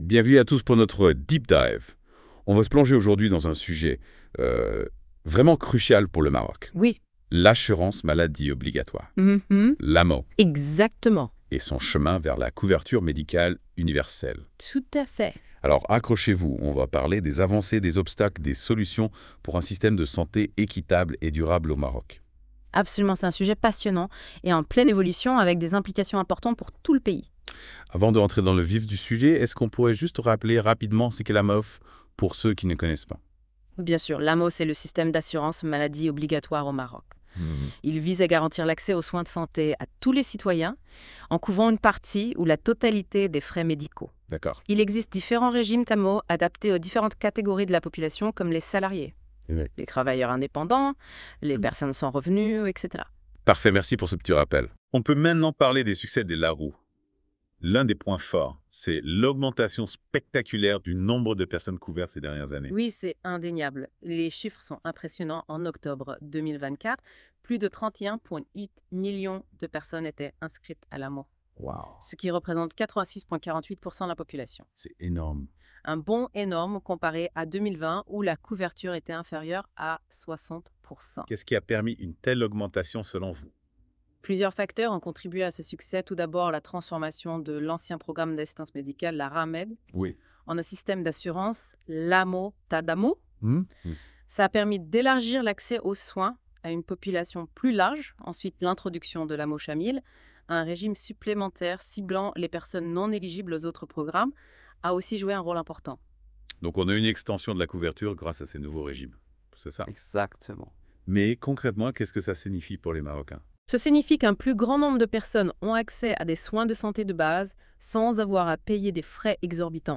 Podcast : Débat sur l'avis du CESE sur L'AMO
A ècouter ici (37.12 Mo) Nos chroniqueurs débattent de l'avis du Conseil Économique, Social et Environnemental (CESE) du Maroc qui analyse la généralisation de l’Assurance Maladie Obligatoire (AMO). Le CESE a examinè les réalisations de la réforme, mais souligne également les défis à relever, comme l’universalité de la couverture, l’équité de l’accès aux soins, la qualité des services de santé et la soutenabilité financière du système.